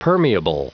Prononciation du mot permeable en anglais (fichier audio)
Prononciation du mot : permeable